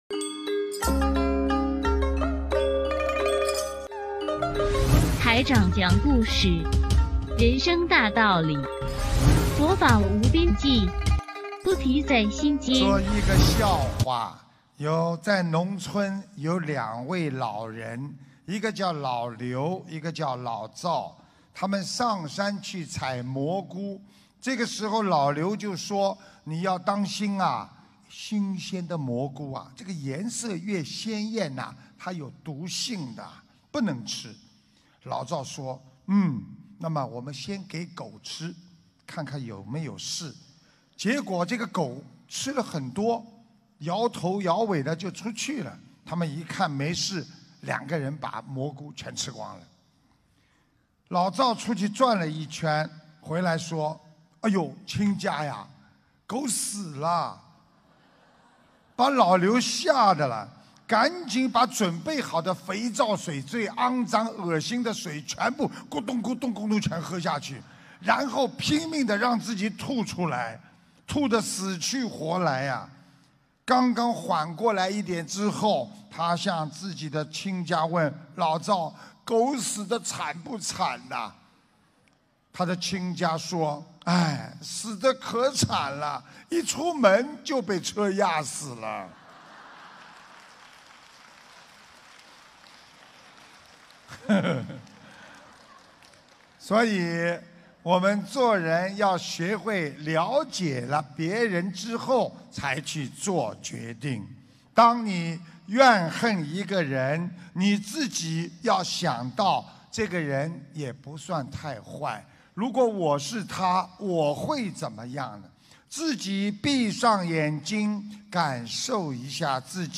音频：這蘑菇有毒·师父讲笑话